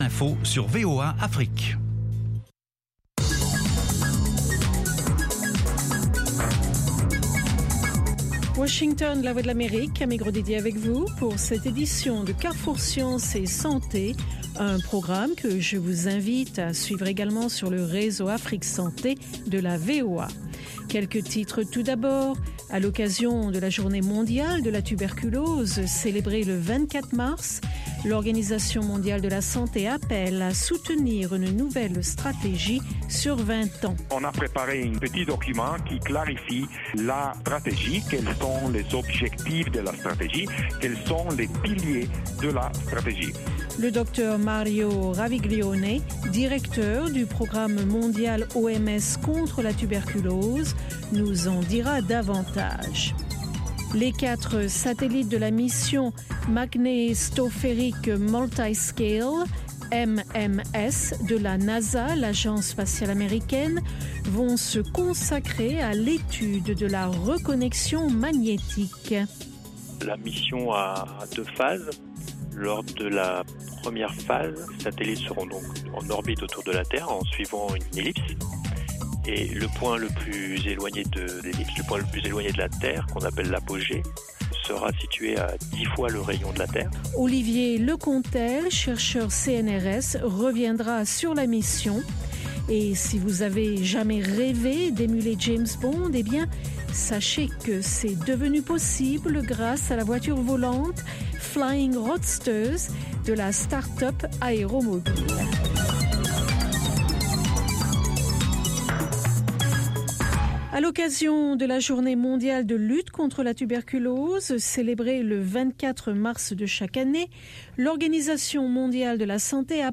Carrefour Sciences et Santé vous offre sur la VOA les dernières découvertes en matière de technologie et de recherche médicale. Il vous propose aussi des reportages sur le terrain concernant les maladies endémiques du continent africain.